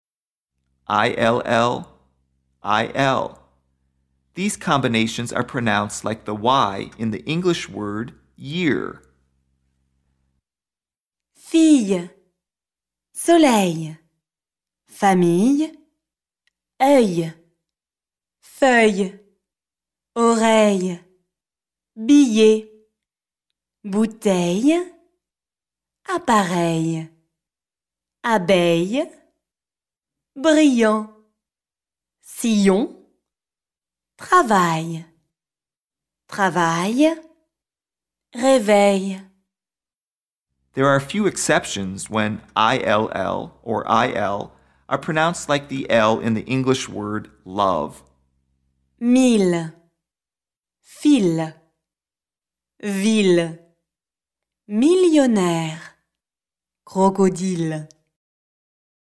PRONONCIATION
ill – il – These combinations are pronounced like the “y” in the English word “year.”
There are a few exceptions when the “ill” or “il” is pronounced like the “l” in the English word “love”: mille, fil, ville, millionaire, crocodile